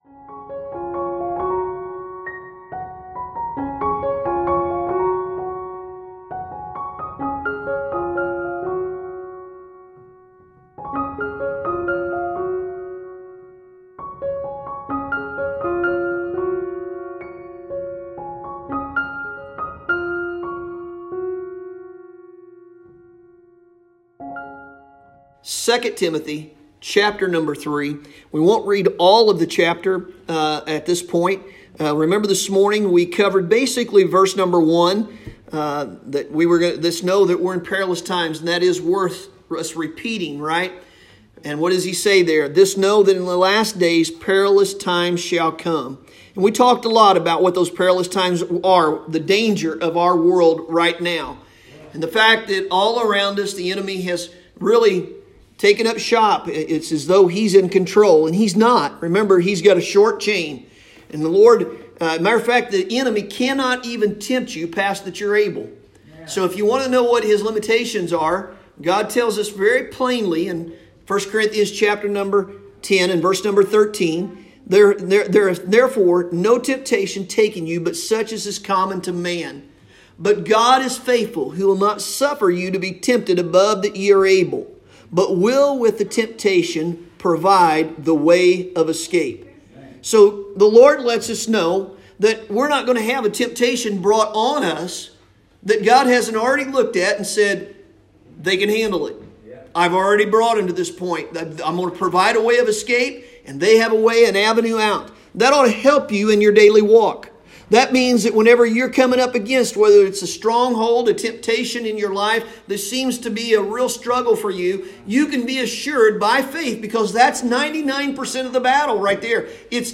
Sunday Evening – January 17, 2021